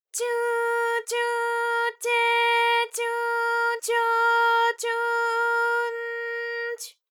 ALYS-DB-001-JPN - First Japanese UTAU vocal library of ALYS.
tyu_tyu_tye_tyu_tyo_tyu_n_ty.wav